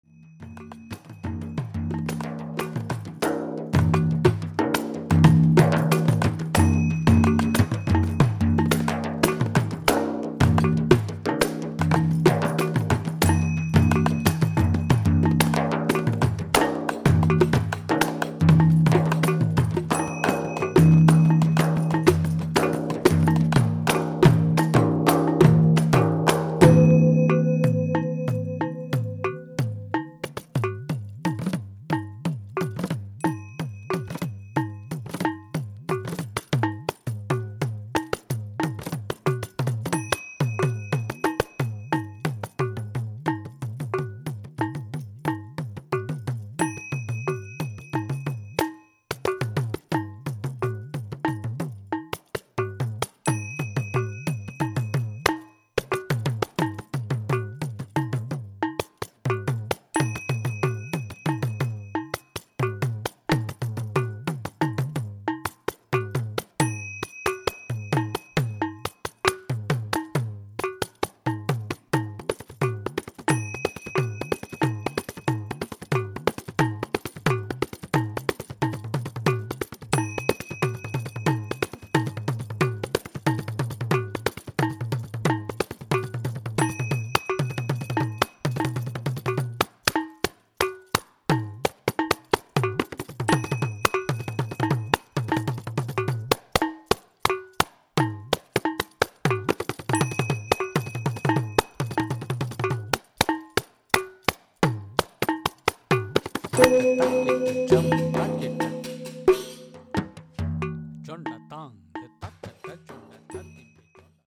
mridangam, kanjira, konakol
riqq, kajar, tala cymbal, gong
frame drum